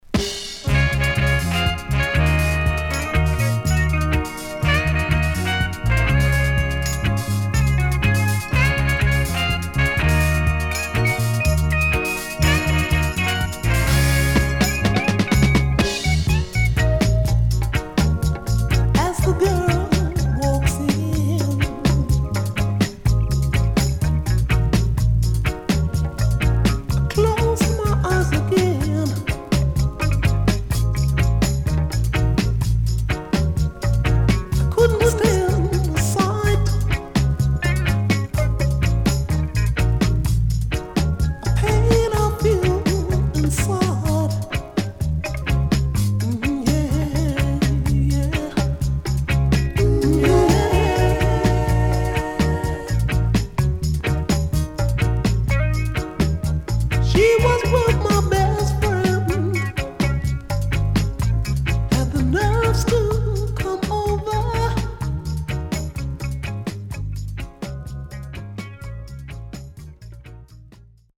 SIDE A:少しジリジリしたノイズあり、序盤スプレーノイズ入ります。